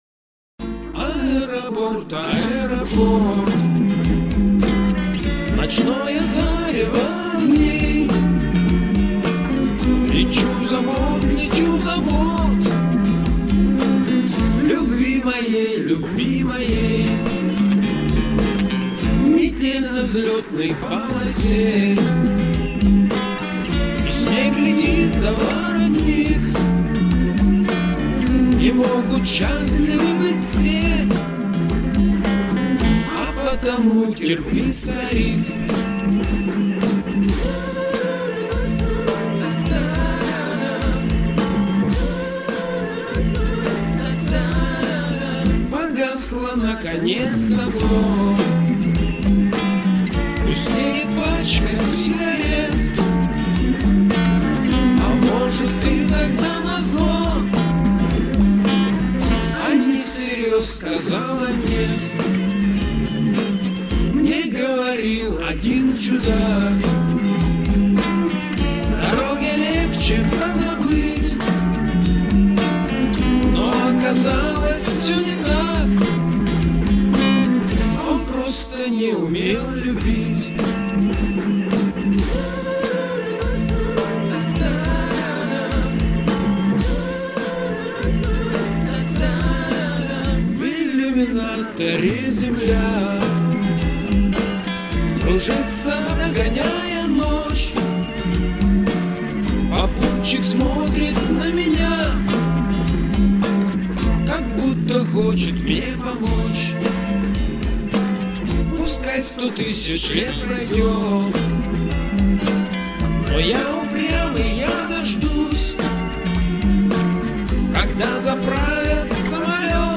Поп (4932)